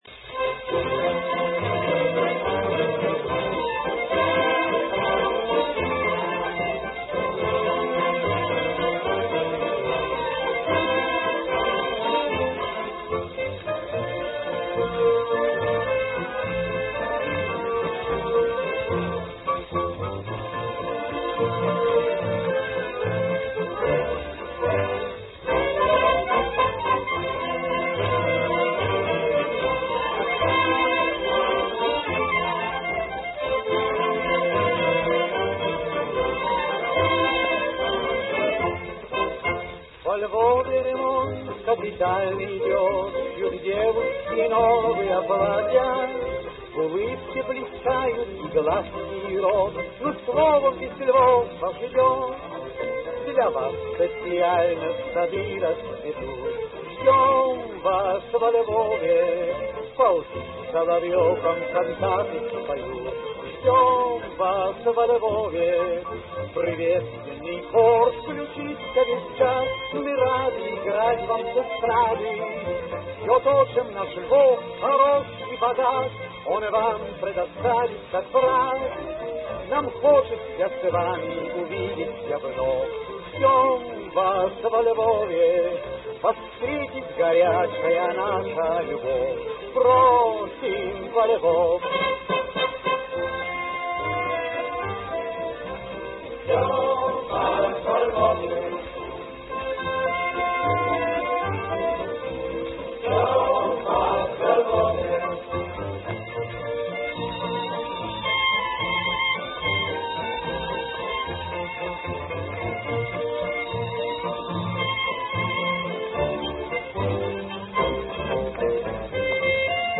Грампластинка